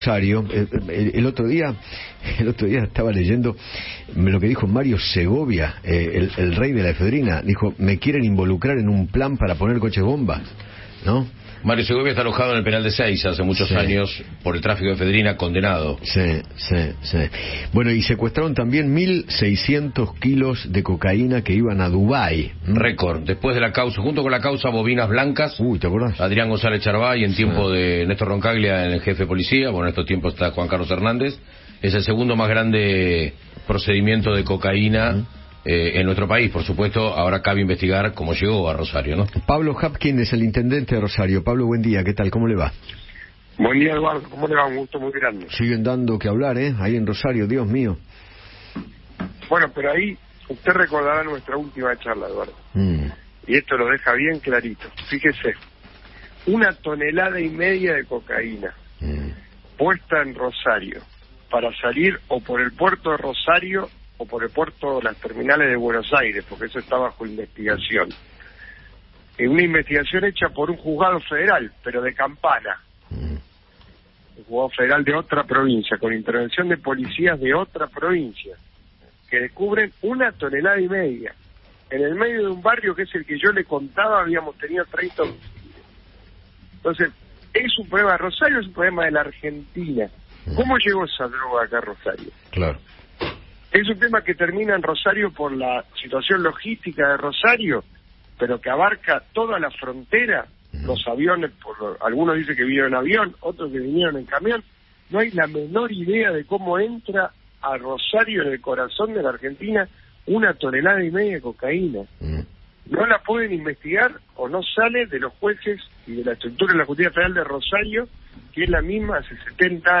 Pablo Javkin, intendente de Rosario, habló con Eduardo Feinmann sobre el secuestro de 1600 kilos de cocaína que iban a Dubai.